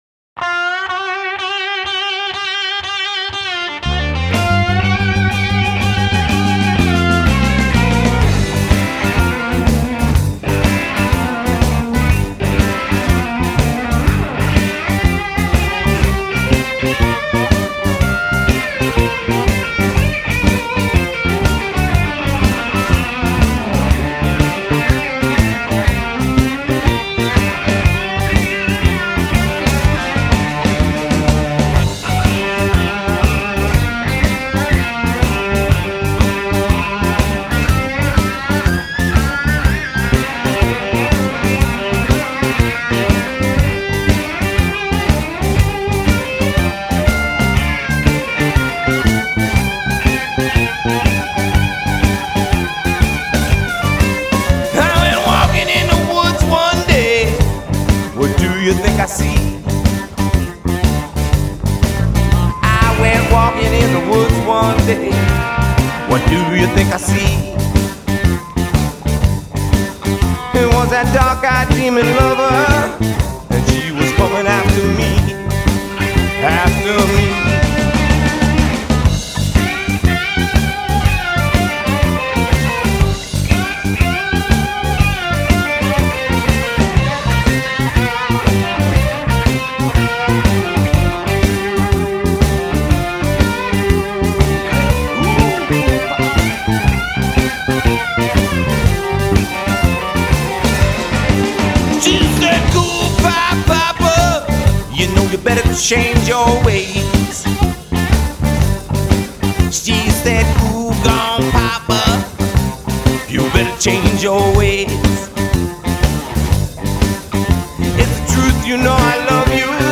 Electric Bass